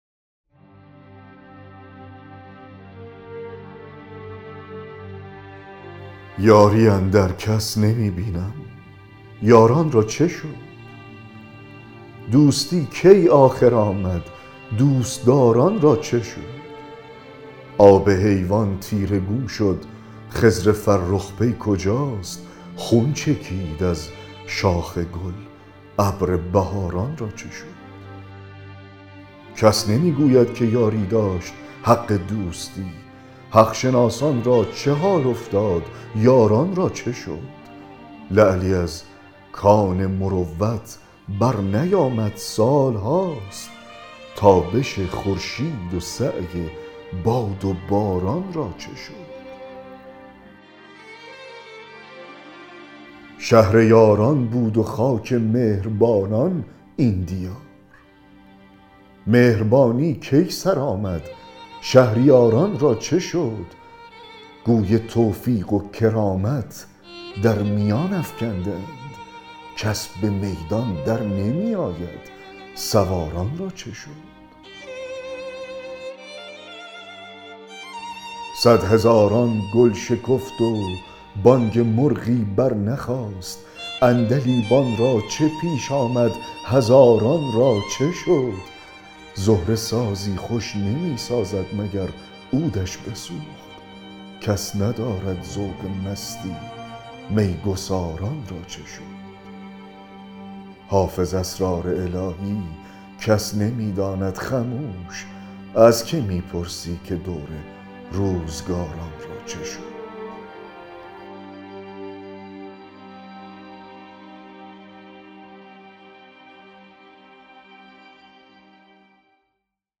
دکلمه غزل 169 حافظ
دکلمه-غزل-169-حافظ-یاری-اندر-کس-نمی-بینم-یاران-را-چه-شد.mp3